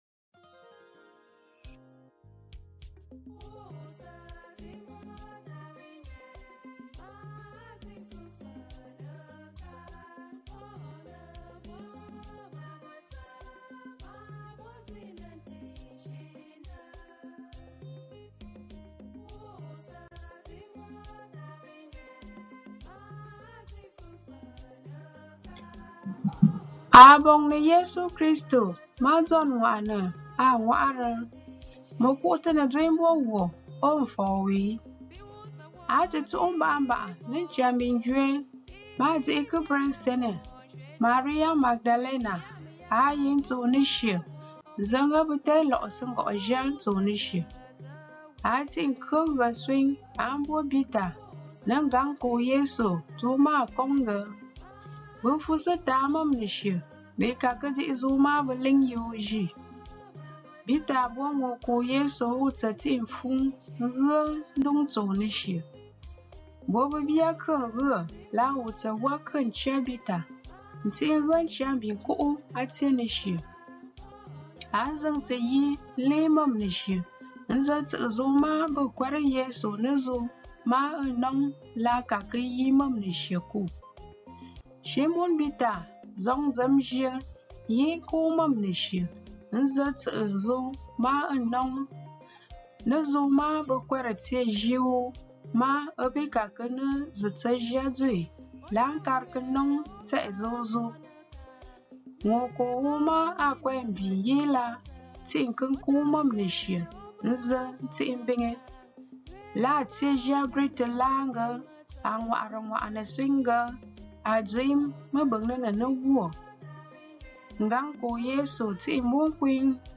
Gospel Readings in Nkwen Language For Year B